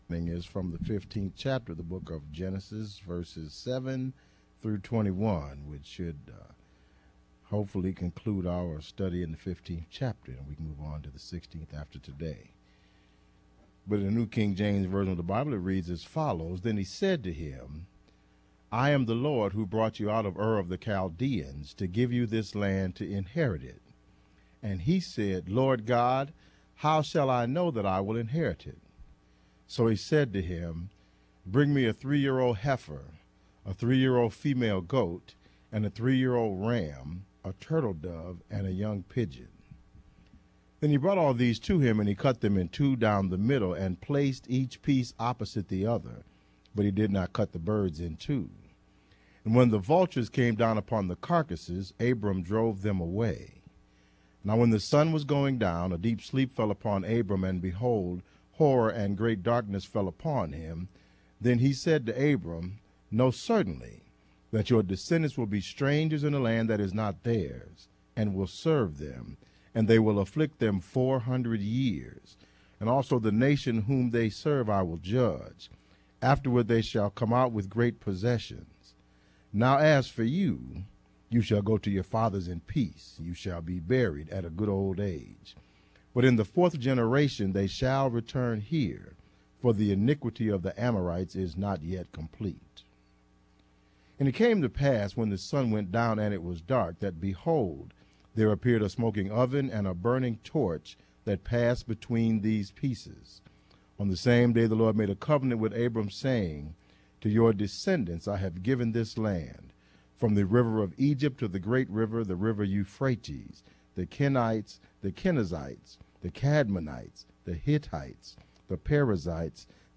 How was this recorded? Family Life Baptist Church : The Covenant of Inheritance